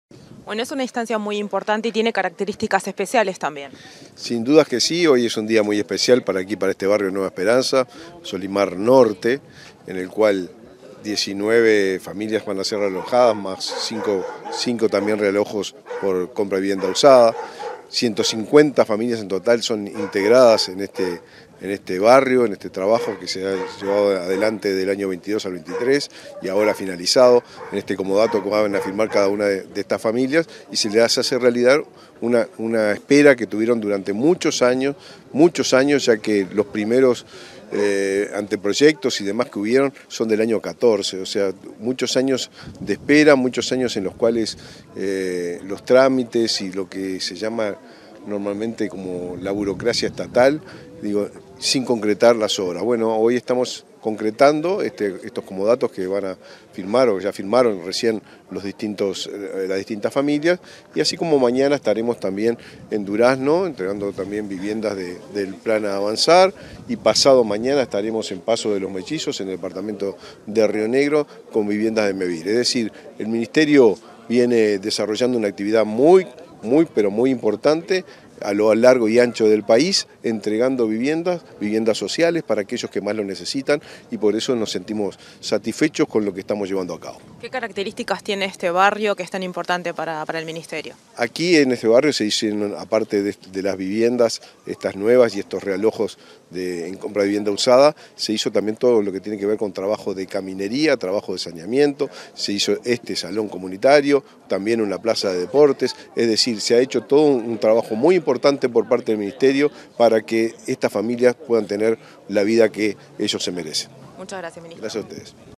Entrevista al ministro de Vivienda y Ordenamiento Territorial, Raúl Lozano